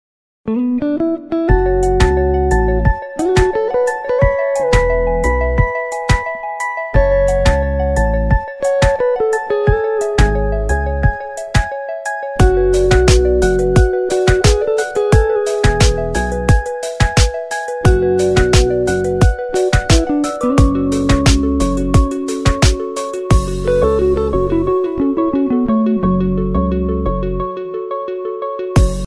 ژانر: بی کلام
اهنگ زنگ شیک ساده ۲